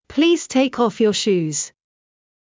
ﾌﾟﾘｰｽﾞ ﾃｲｸ ｵﾌ ﾕｱ ｼｭｰｽﾞ